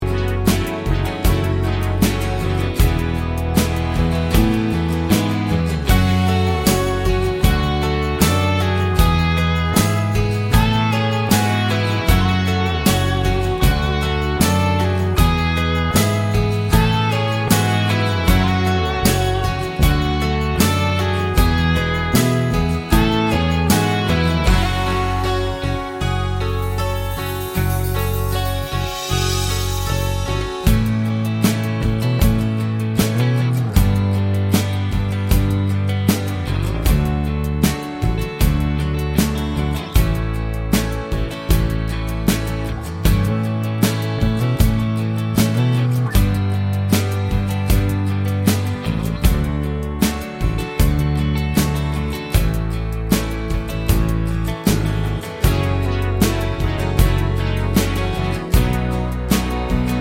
no Backing Vocals Soft Rock 3:52 Buy £1.50